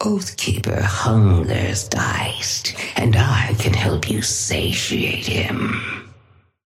Sapphire Flame voice line - Oathkeeper hungers, Geist, and I can help you satiate him.
Patron_female_ally_ghost_start_01.mp3